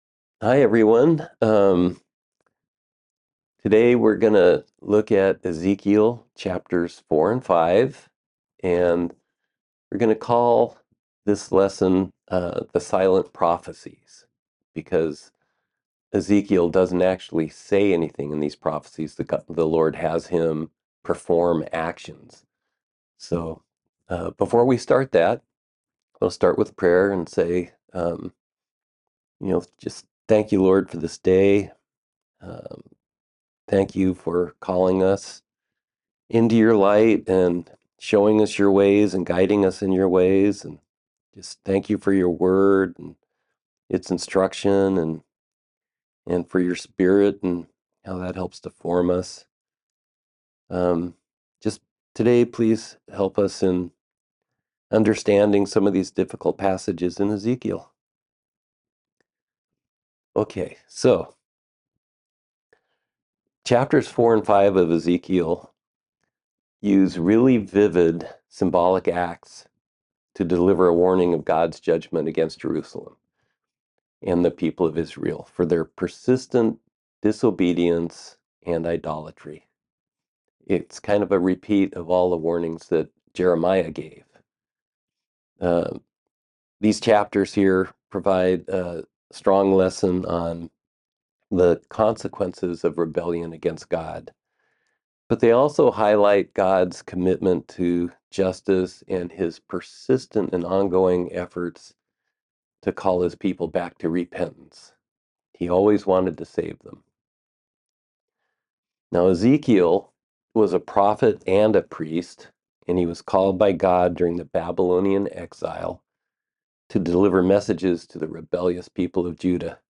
Verse by verse Bible study.